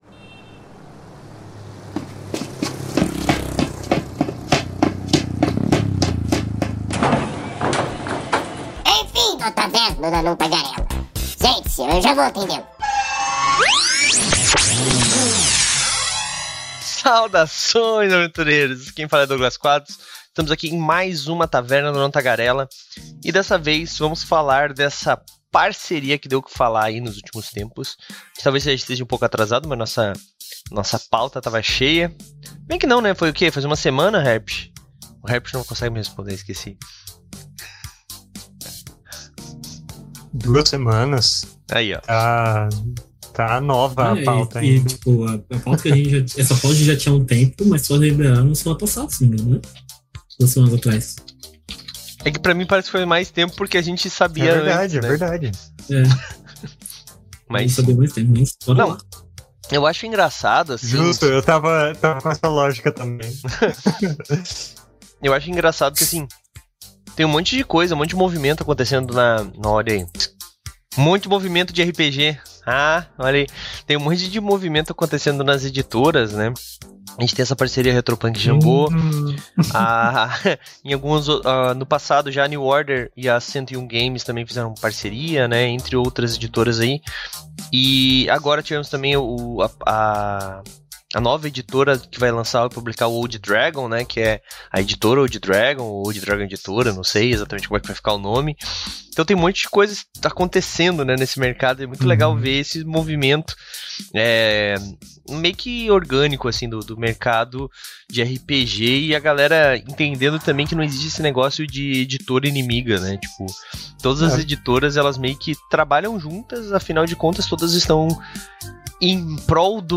Conversa sobre a Parceria Jambô e Retropunk nessa taverna. Venha saber do que se trata essa parceria entre as duas editoras.
A Taverna do Anão Tagarela é uma iniciativa do site Movimento RPG, que vai ao ar ao vivo na Twitch toda a segunda-feira e posteriormente é convertida em Podcast.